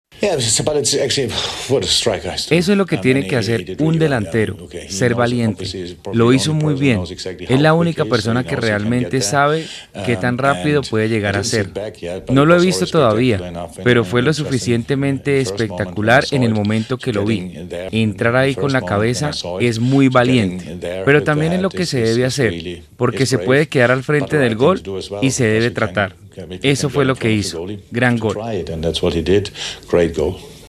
(Jurgen Klopp, técnico del Liverpool)
“Es lo que tiene que hacer un delantero, ser valiente. Lo hizo muy bien. Él es la única persona que realmente sabe que tan rápido puede llegar a ser”, sostuvo Klopp en entrevista con Sky Sports.